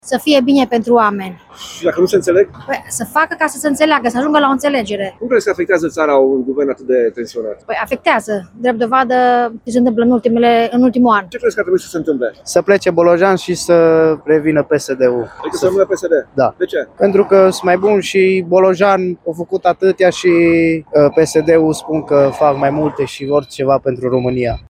„Să plece Bolojan și să revină PSD”, a declarat un bărbat